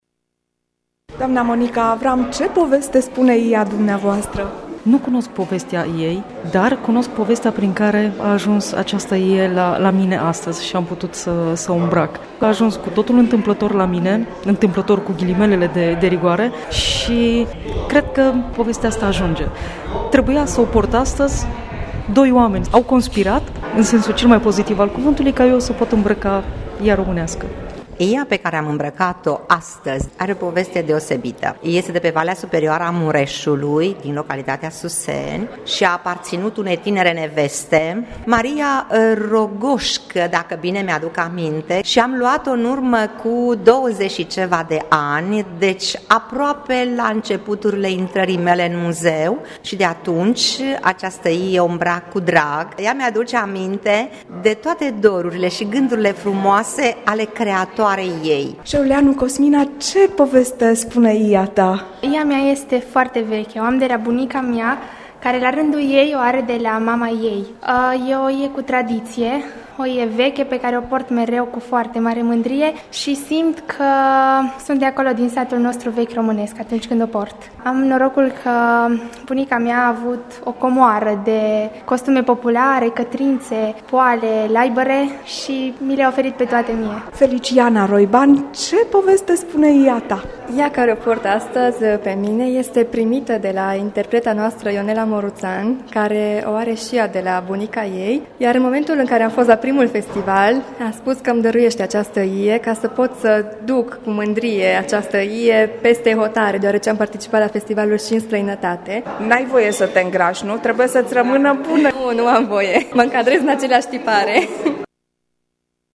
Despre ea „minunea de cămaşă”ne vor vorbi, cu emoţie în glas, aşa cum au făcut-o la întâlnirea cu iz folcloric: